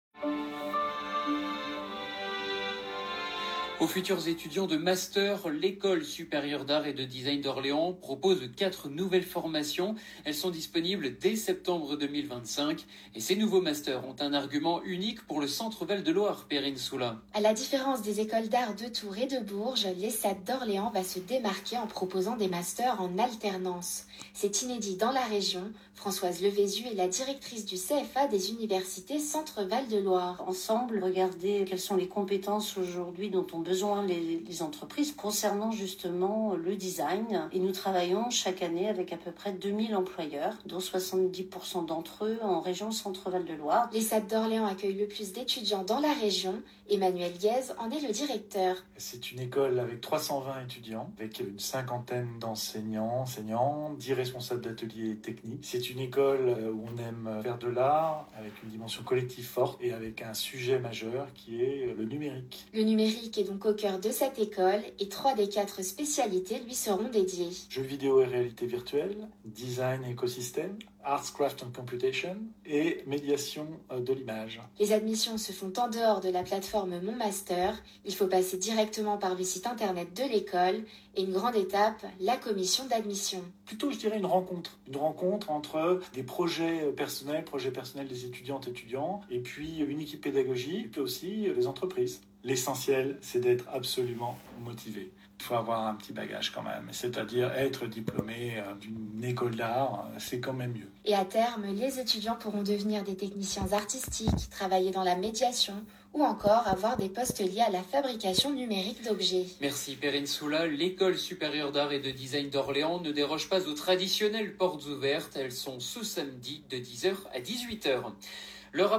Nous avons également eu une place dans le flash info matinale de la radio RCF Loiret à propos de notre partenariat avec l’ESAD Orléans.